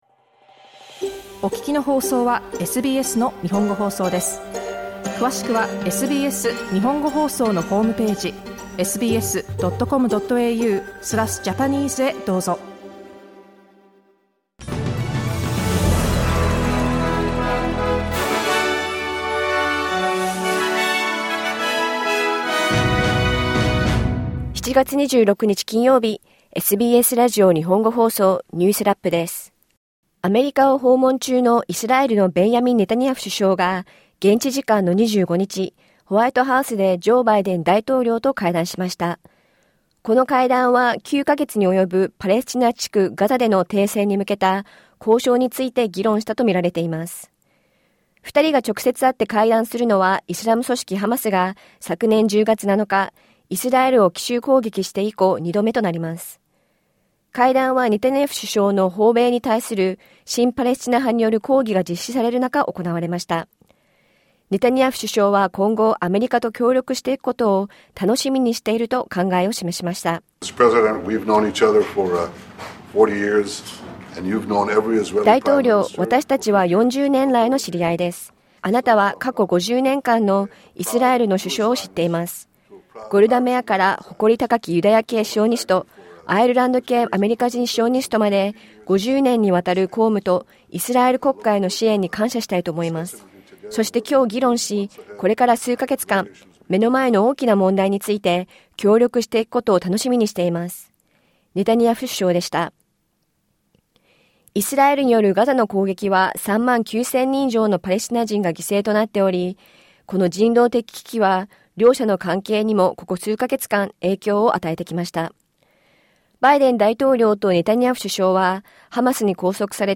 イスラエルのネタニヤフ首相がバイデン大統領とホワイトハウスで会談しました。1週間のニュースを振り返るニュースラップです。